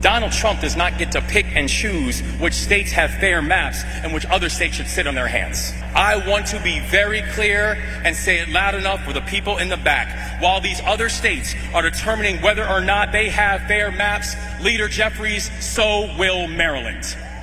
Maryland Governor Wes Moore continued the war of words between himself and President Trump over the weekend, making remarks at the Congressional Black Caucus Foundation Dinner. Moore said that states redrawing districts to obtain votes amounts to redlining, gerrymandering black leaders out of office…